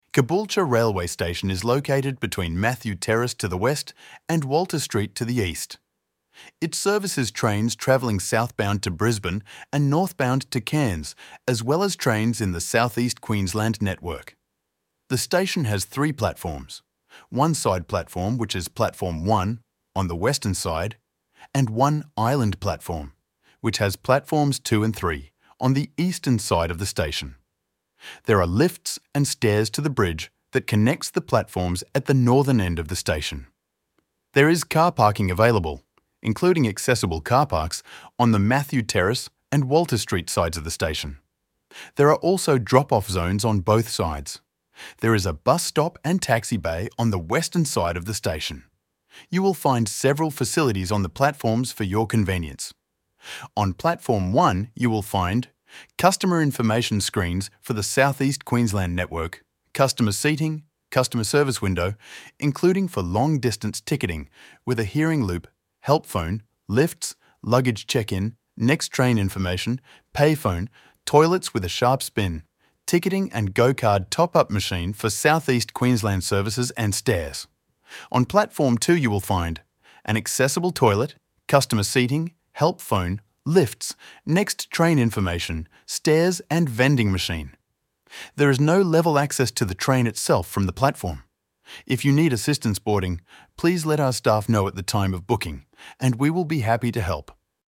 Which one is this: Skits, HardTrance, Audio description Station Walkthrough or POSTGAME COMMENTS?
Audio description Station Walkthrough